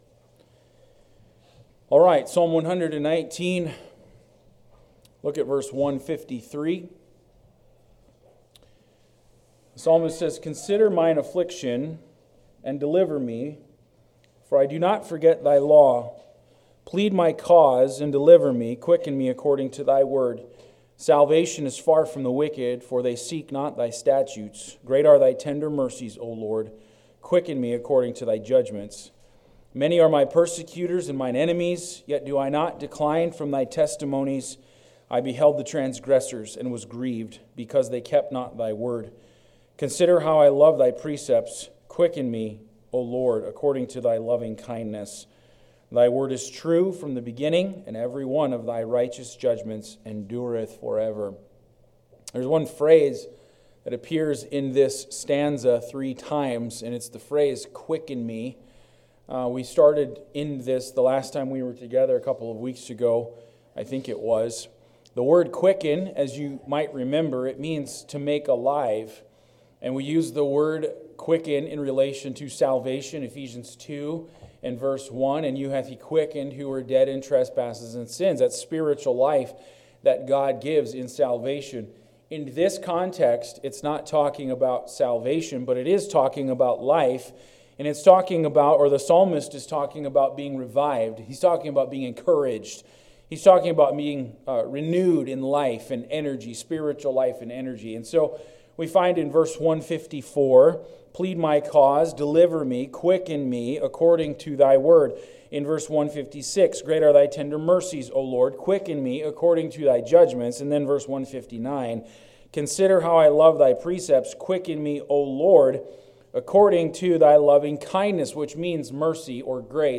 1 The War of the Ages: A Battle Beyond Earth 26:53 Play Pause 3d ago 26:53 Play Pause Lejátszás később Lejátszás később Listák Tetszik Kedvelt 26:53 Join us in this compelling sermon as we delve into the profound mysteries of Revelation, focusing on Chapter 12 and the intriguing figure of the male child.